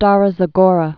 (stärə zə-gôrə)